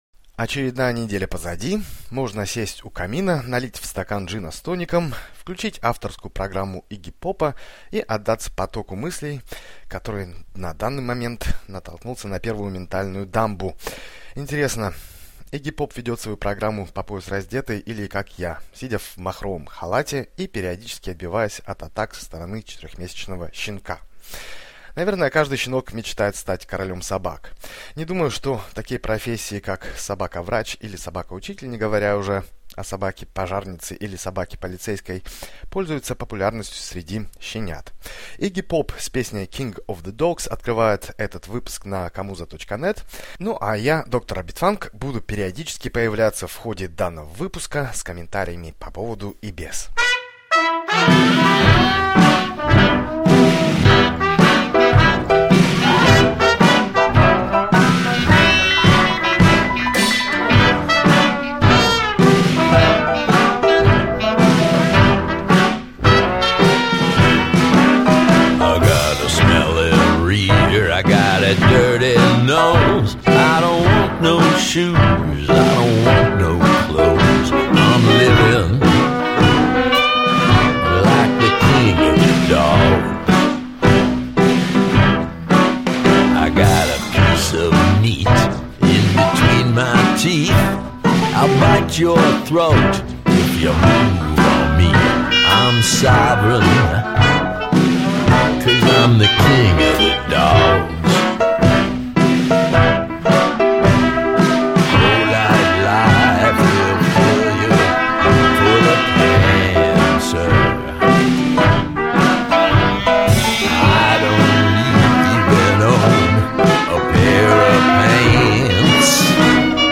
еженедельный музыкальный радио подкаст
электронно-танцевальные треки